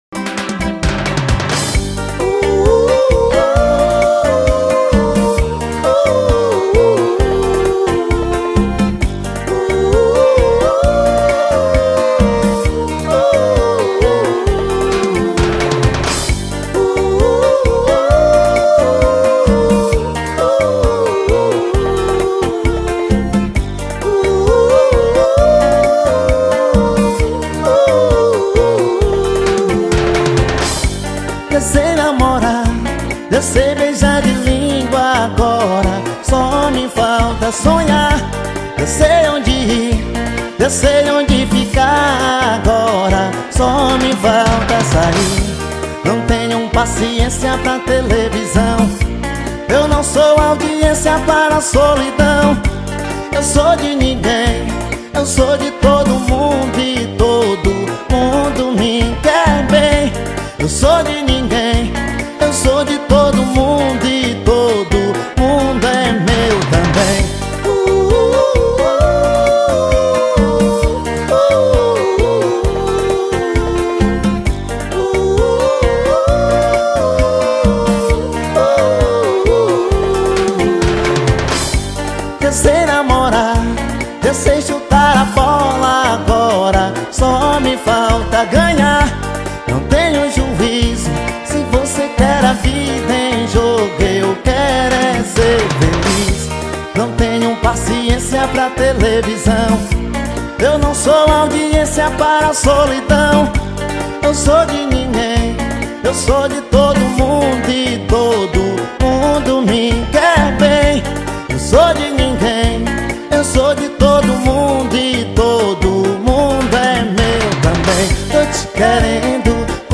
2024-06-26 18:34:32 Gênero: MPB Views